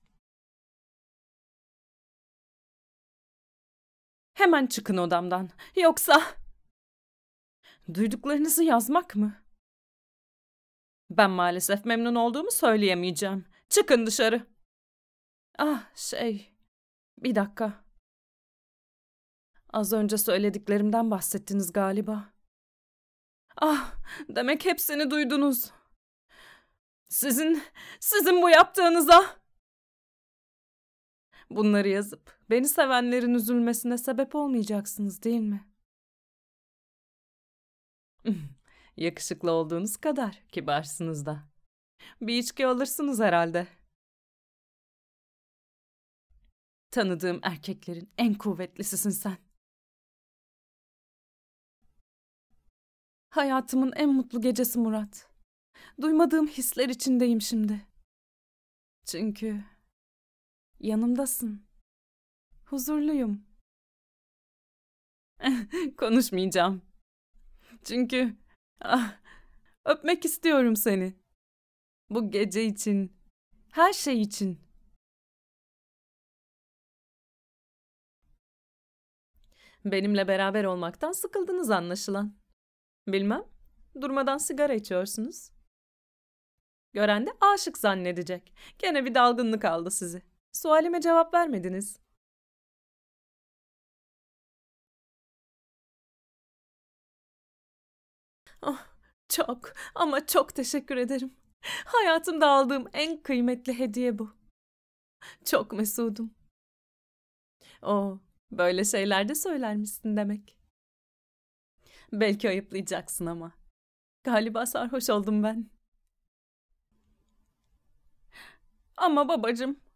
Female
Warm, friendly, deep, peaceful, feminine, professional, maternal.
Character / Cartoon